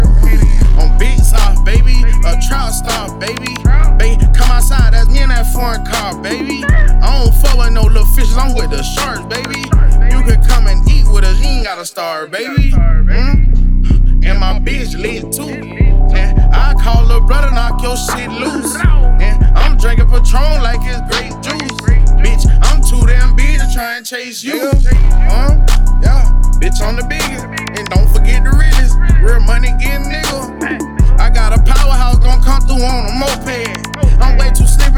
Жанр: Рэп и хип-хоп